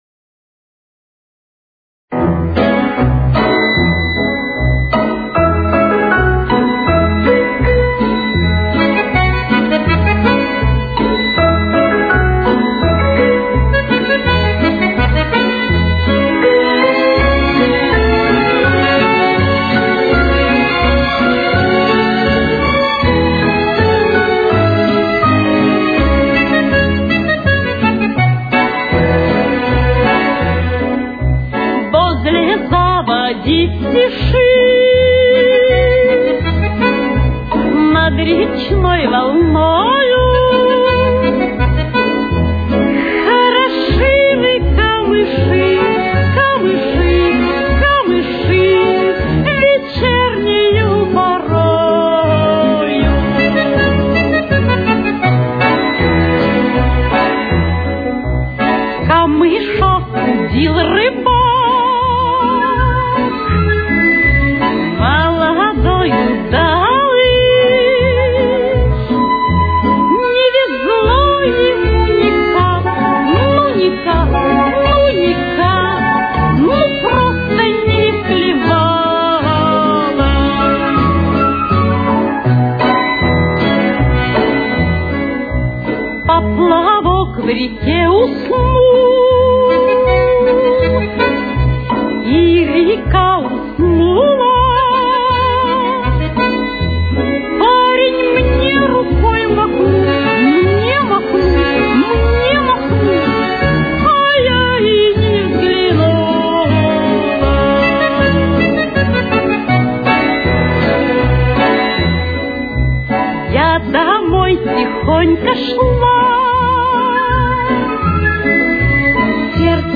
Темп: 83.